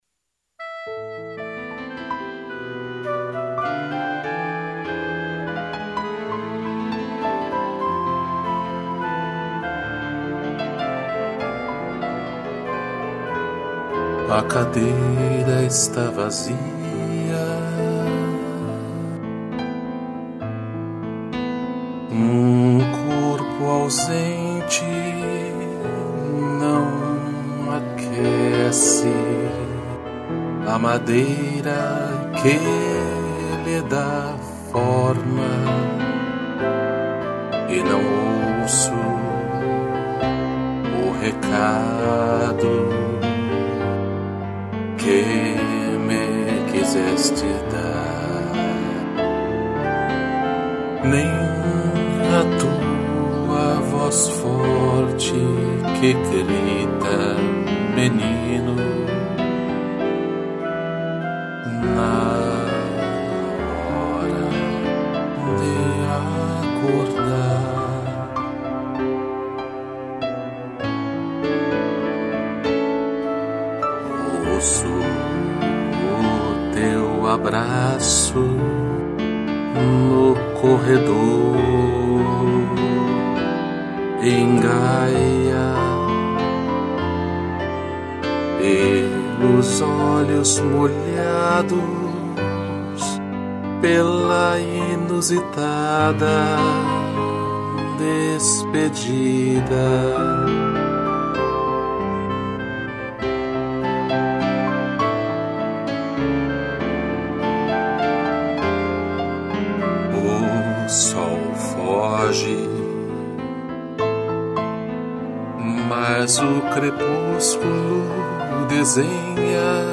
2 pianos, flauta e clarineta